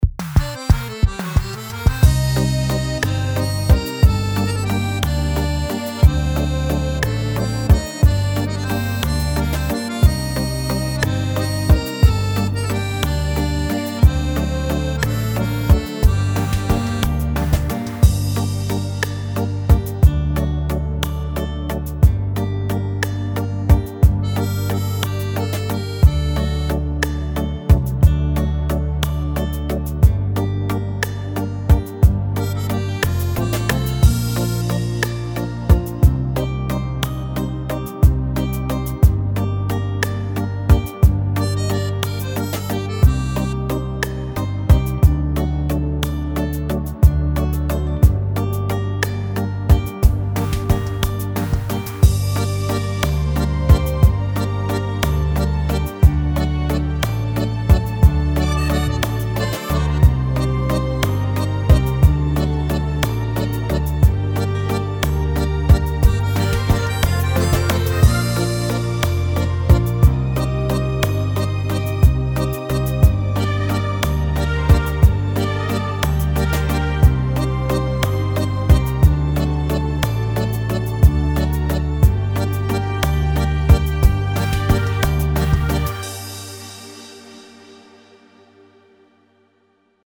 вальса